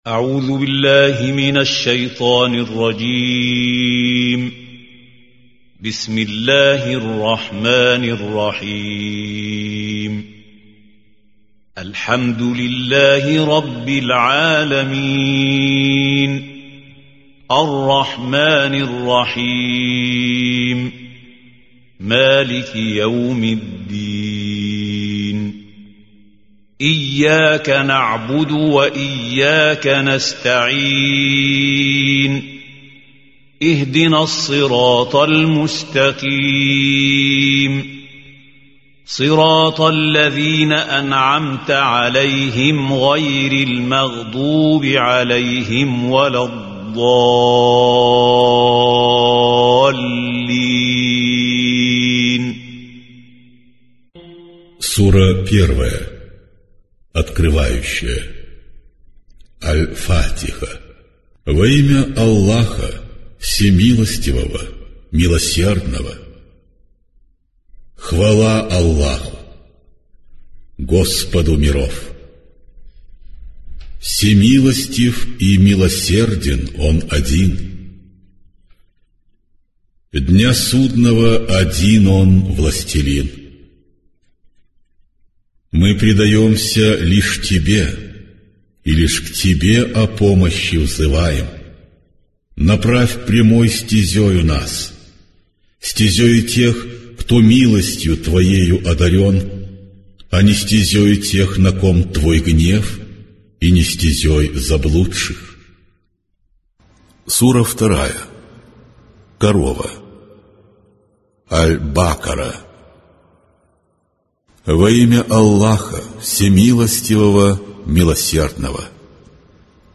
Аудиокнига Коран. На арабском и русском языках | Библиотека аудиокниг